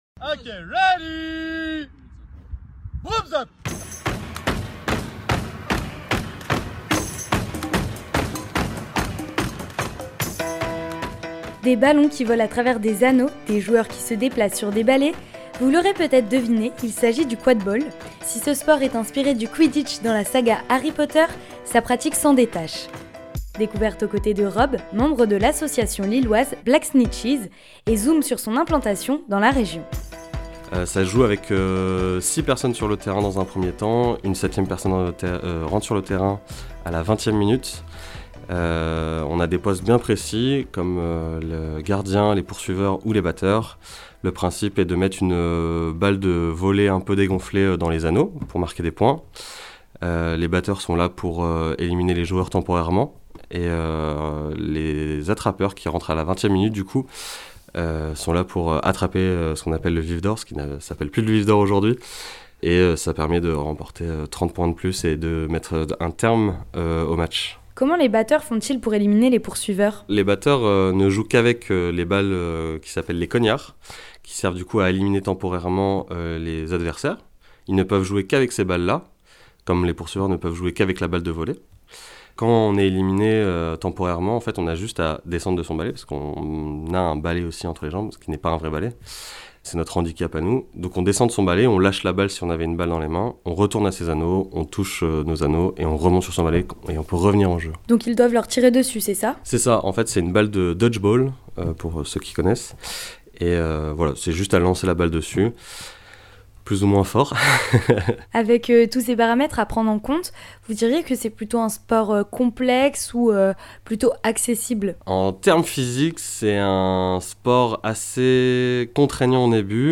3. REPORTAGES